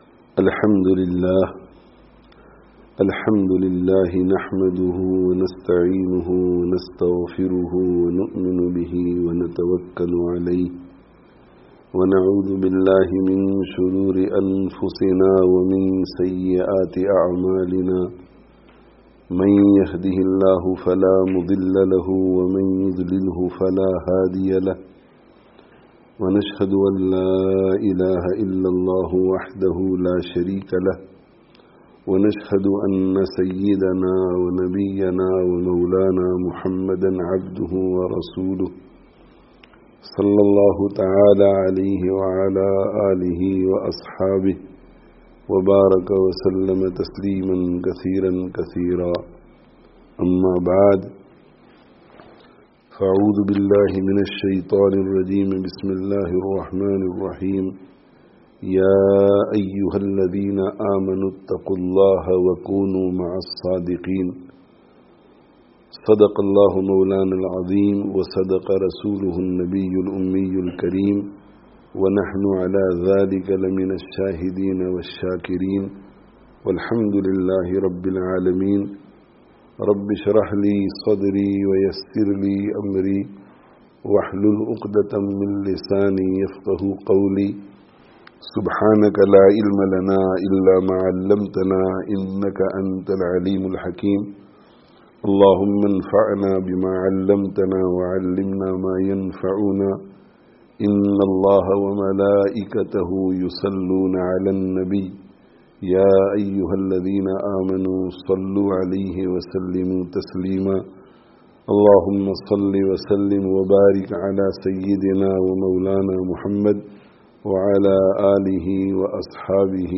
English Dars of Hadith